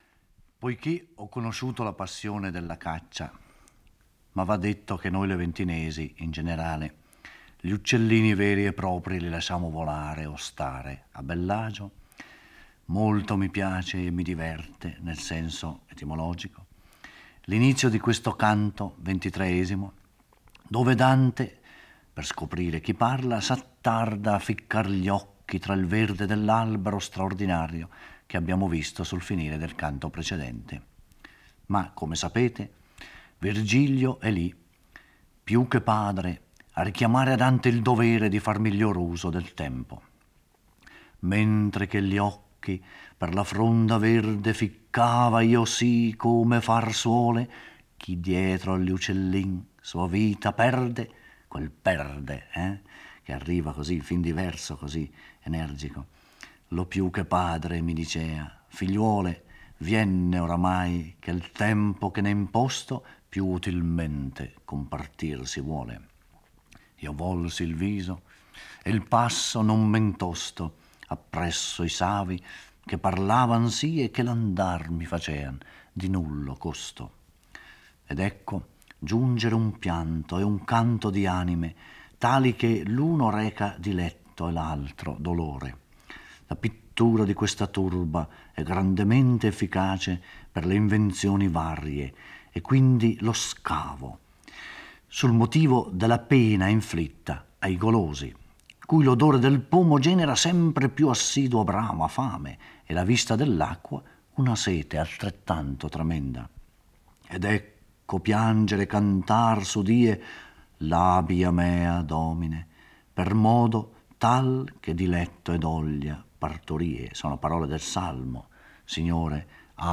Giorgio Orelli legge e commenta il XXIII canto del Purgatorio.